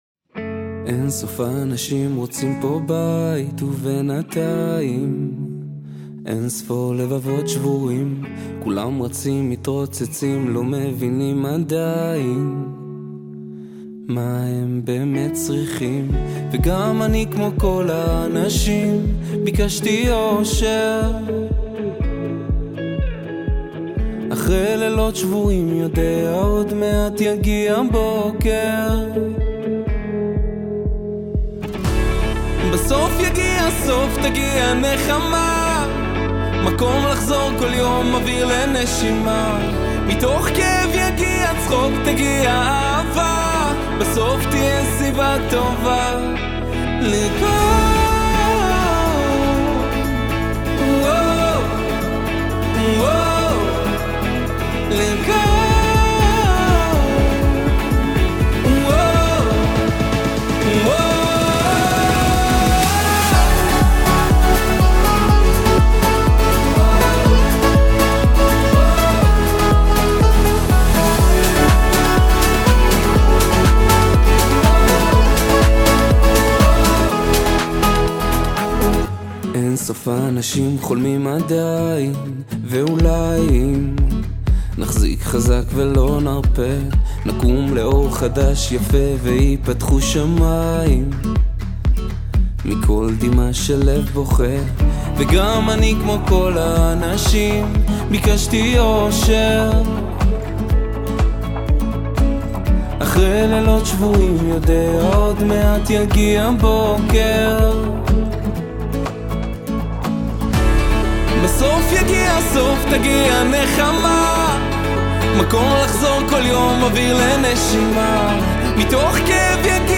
ווקאלי